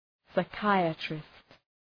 Προφορά
{saı’kaıətrıst}